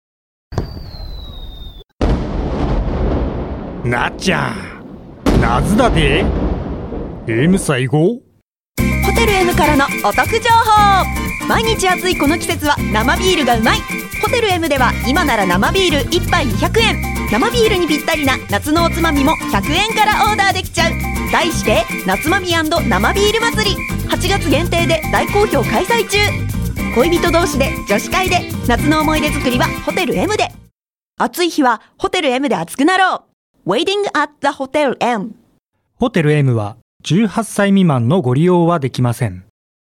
ｍの ラジオCM ナツマミ が 横手かまくらFM で 11日 21時から 流れますよ?。